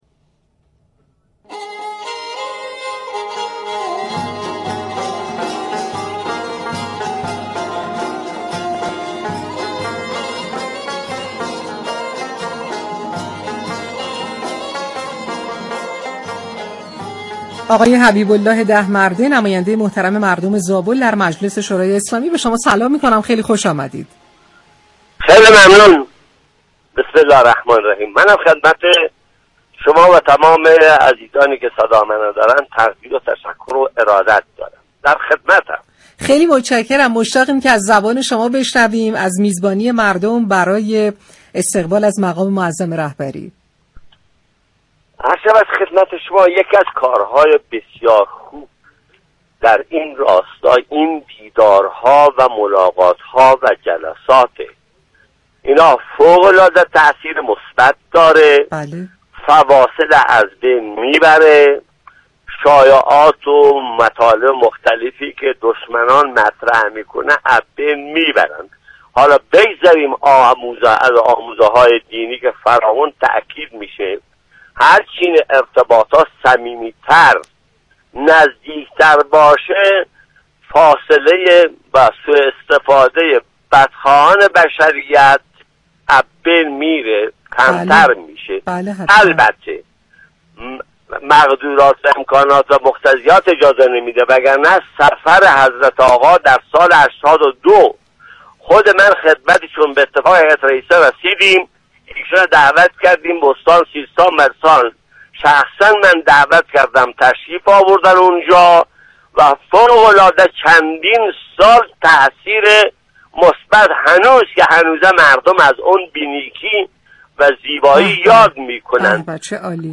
به گزارش پایگاه اطلاع رسانی رادیو تهران، حبیب الله دهمرده نماینده مردم زابل در مجلس شورای اسلامی در گفت و گو با «شهر آفتاب» درخصوص دیدار رهبر انقلاب با مردم استان سیستان و بلوچستان اظهار داشت: در این دیدار همه اقوام، مذاهب و معتمدین مردم از اقشار مختلف حضور دارند این دیدار دشمنان را خنثی می‌كند.